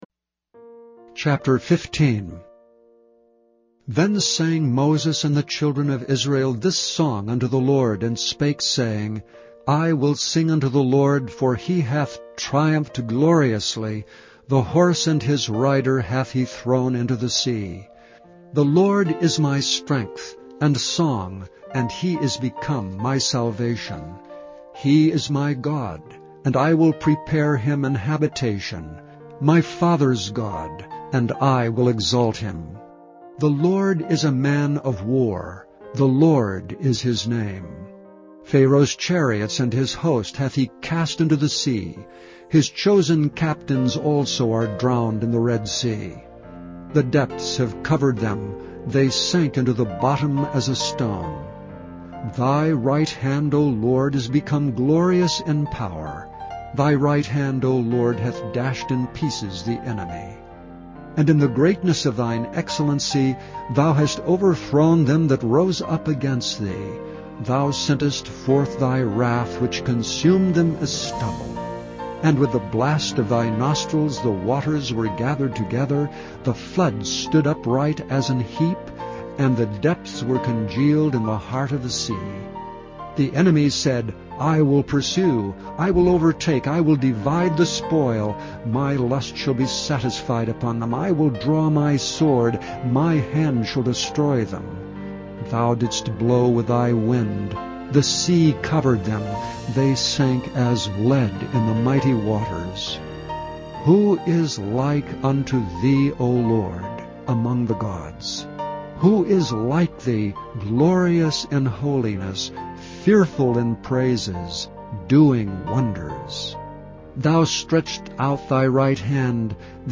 Listen to Exodus 15 Listen to Exodus chapter fifteen being read, or download it to listen to later.